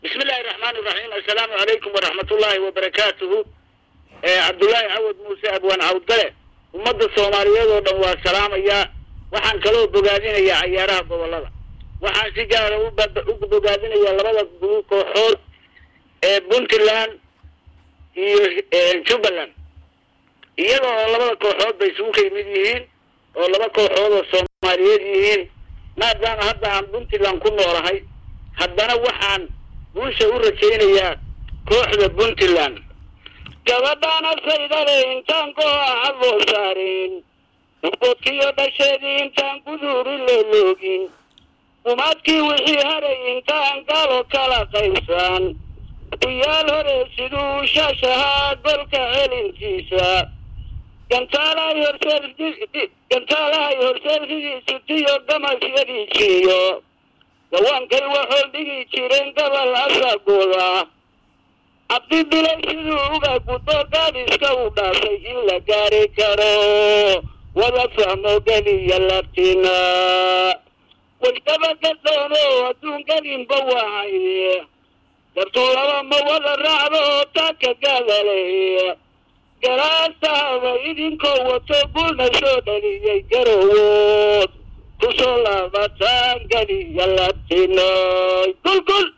Dhagayso Gabayga